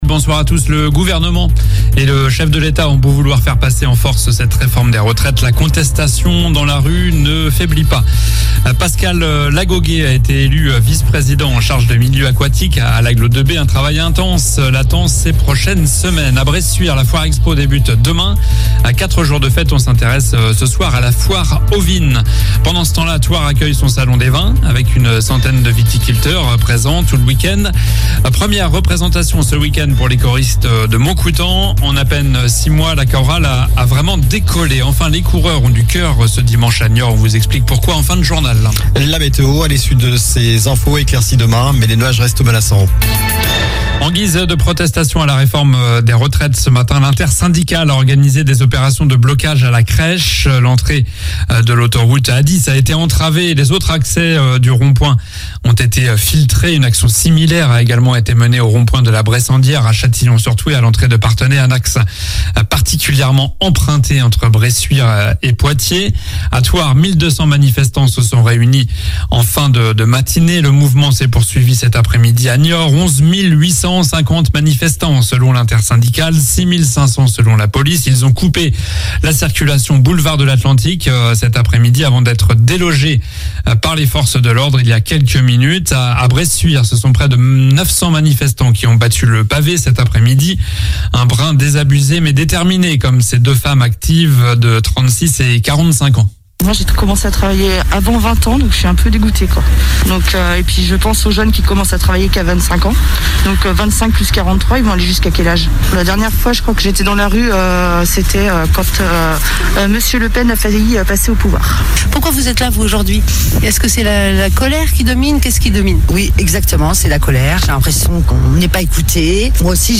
Journal du jeudi 23 mars (soir)